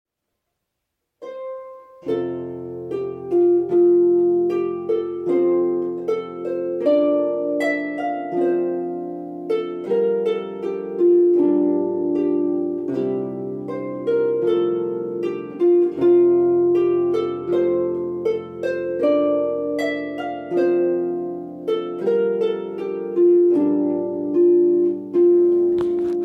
traditional Scottish tune